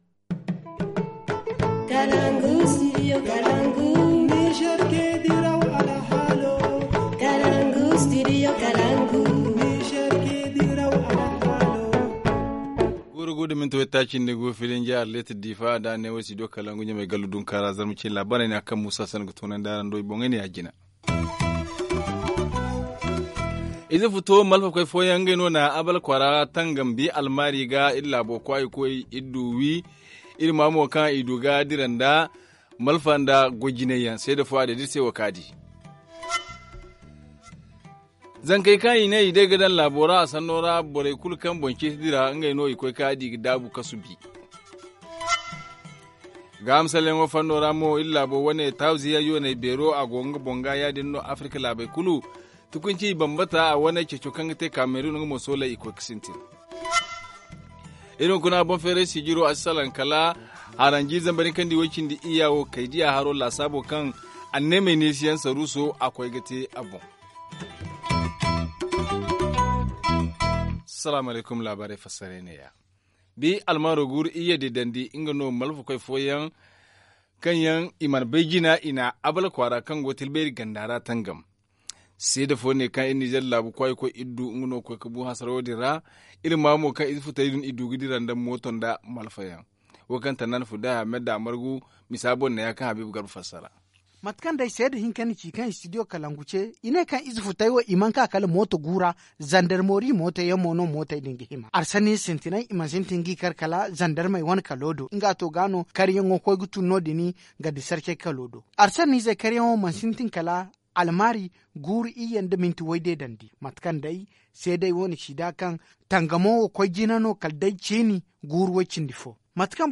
Journal en Francais